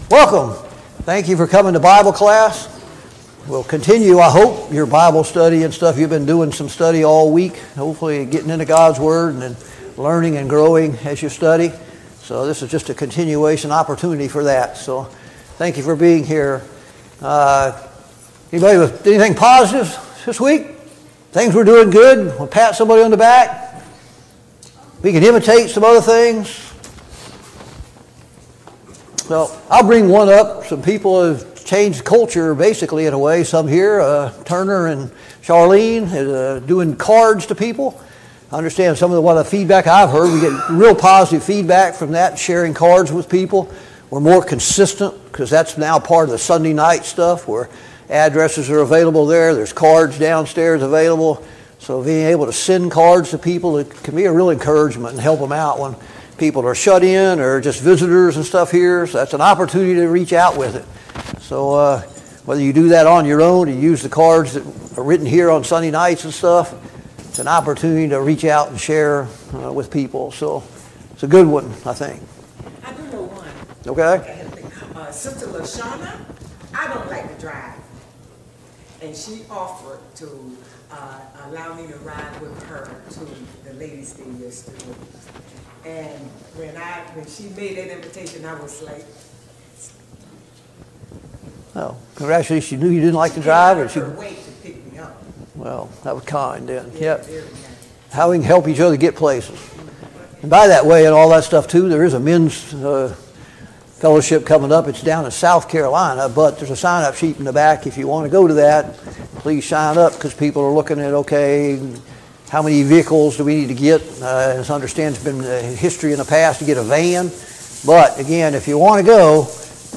Study of Jeremiah Service Type: Sunday Morning Bible Class « Study of Paul’s Minor Epistles